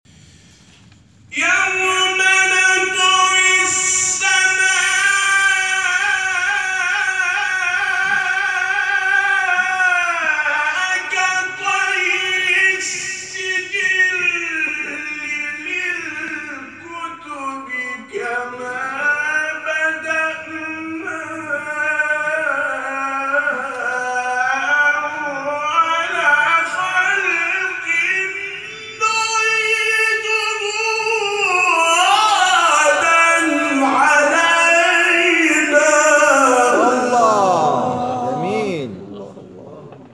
شبکه اجتماعی: نغمات صوتی از تلاوت قاریان برجسته و ممتاز کشور که به‌تازگی در شبکه‌های اجتماعی منتشر شده است، می‌شنوید.